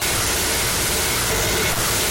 Some Weird Ass Jumpscare Sound Button - Free Download & Play
Games Soundboard826 views